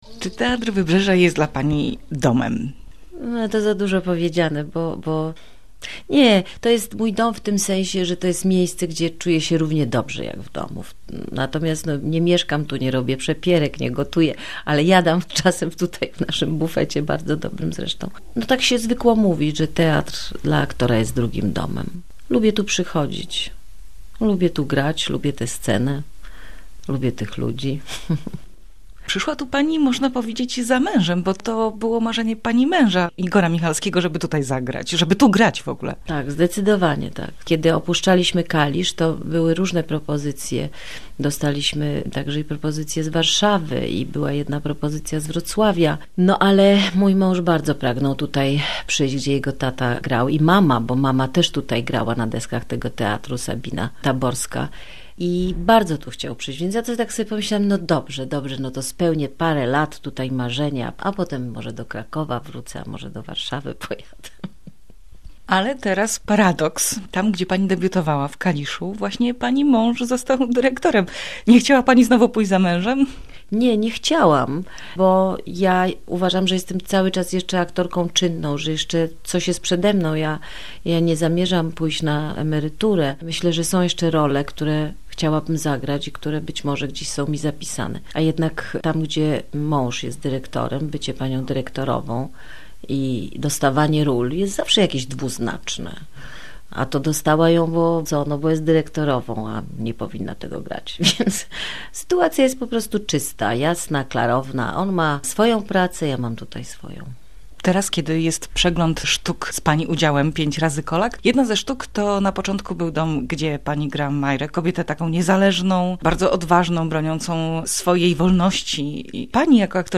Wywiad z aktorką Dorotą Kolak